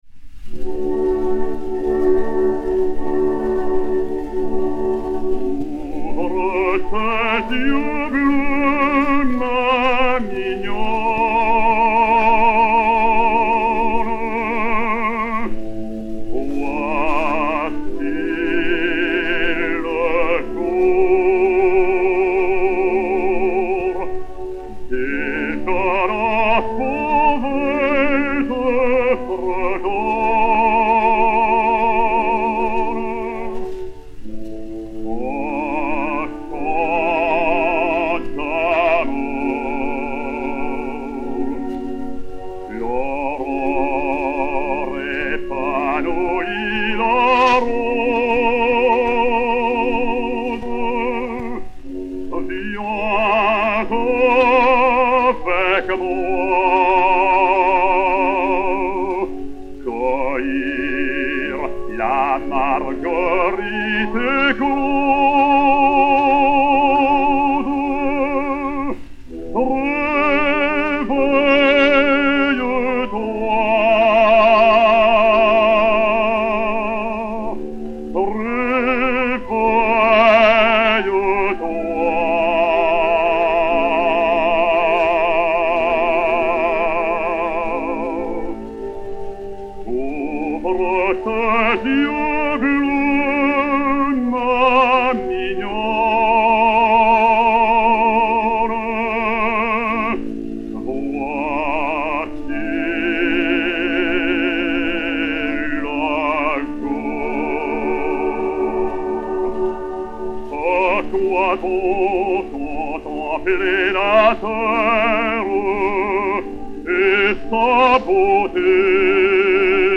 Paul Payan, basse, avec Orchestre